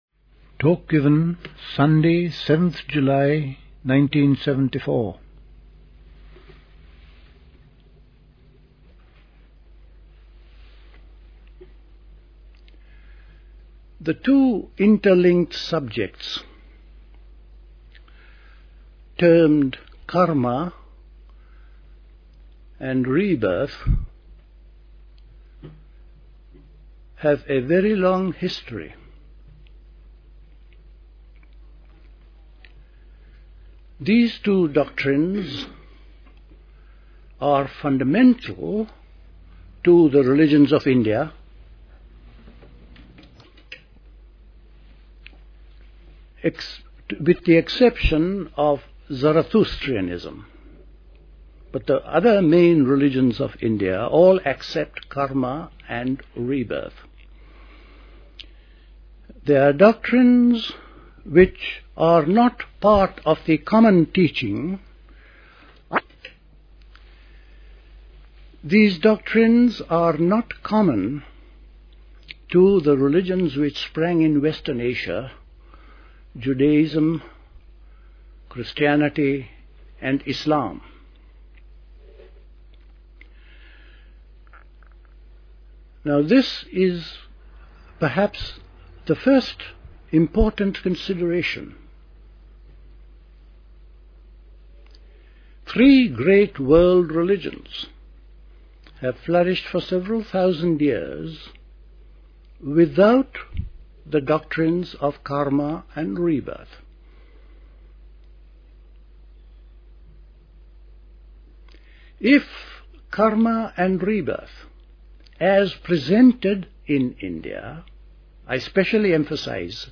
A talk
Dilkusha, Forest Hill, London